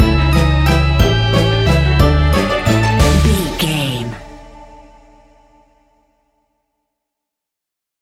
Aeolian/Minor
D
scary
ominous
eerie
playful
strings
synthesiser
percussion
spooky
horror music